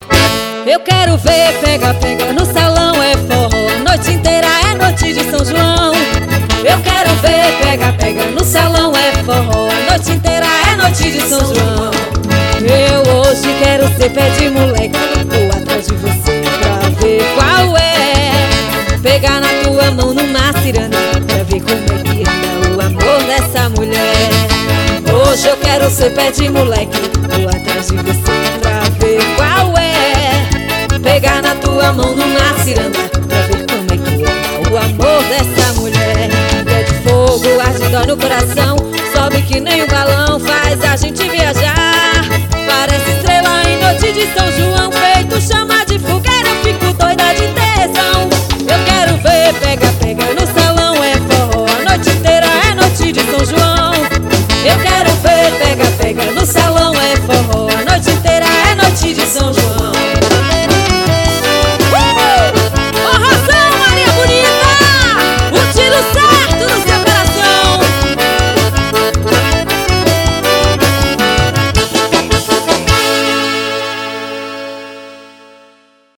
Ao vivo em Fortaleza.